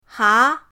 ha2.mp3